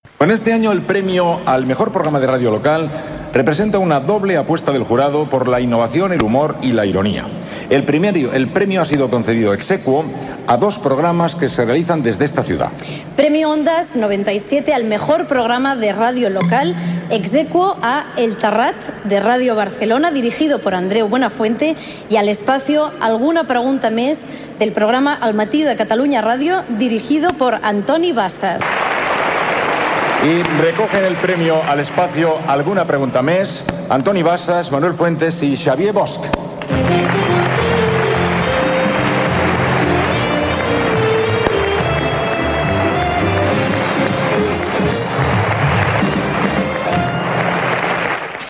Transmissió del lliurament dels quaranta-vuitens Premios Ondas 2001, en la categoria de ràdio local, a la secció "Alguna Pregunta Més" del Matí de Catalunya Ràdio